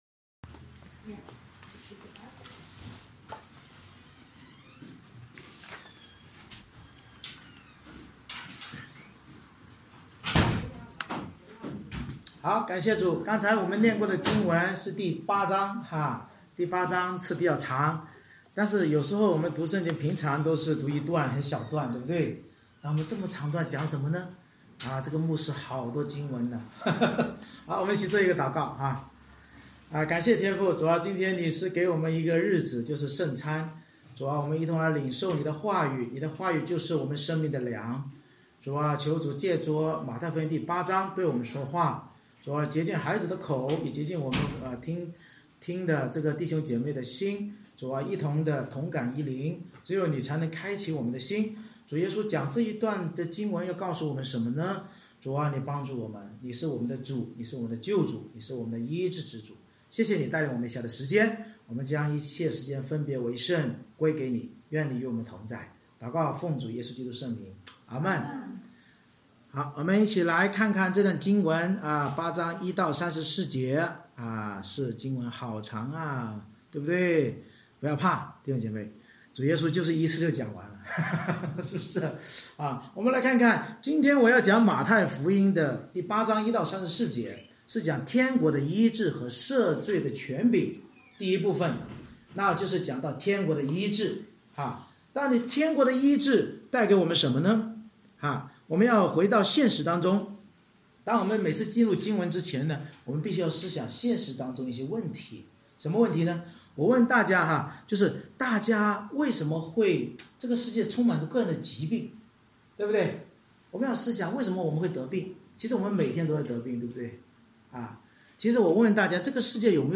马太福音8:1-34 Service Type: 主日崇拜 无论我们是否相信君王耶稣能远距离医治、还是小信或不信耶稣能斥责风和海并赶鬼，旧约先知关于义仆耶稣医病赶鬼预言都必应验。